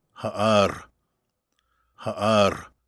like the pause in ”uh-oh’